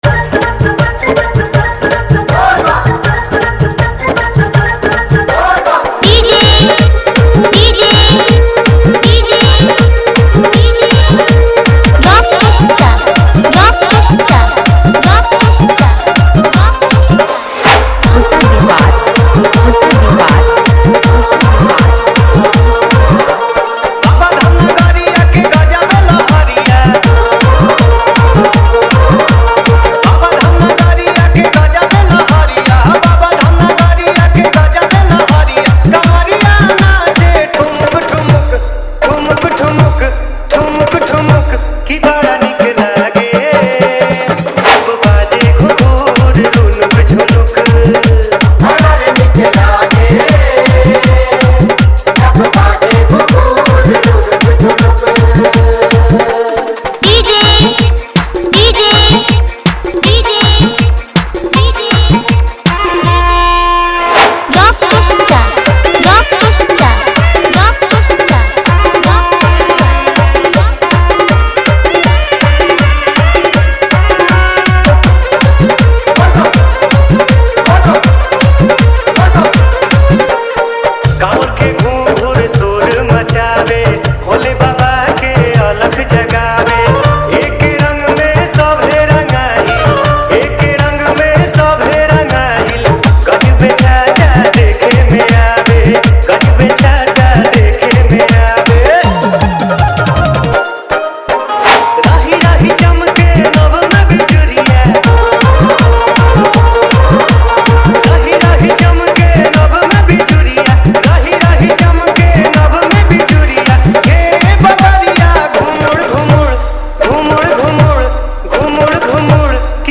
Category:  Bol Bam 2023 Dj Remix Songs